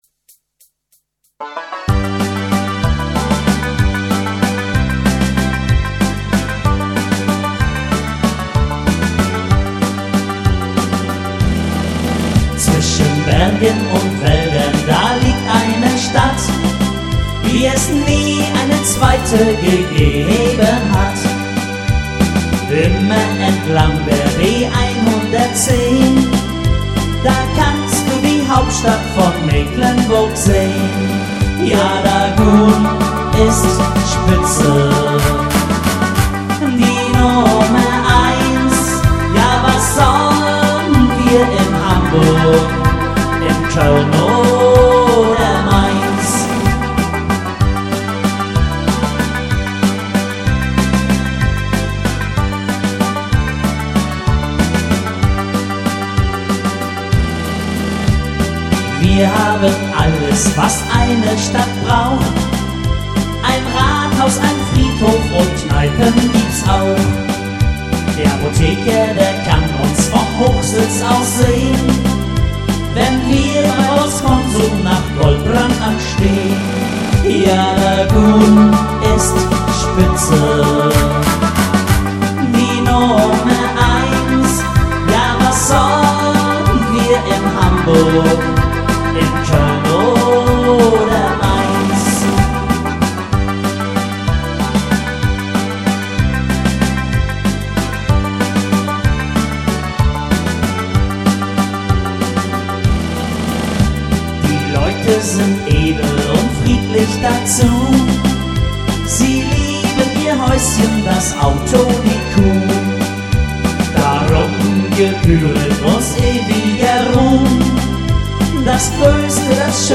Karnevalsschlager.mp3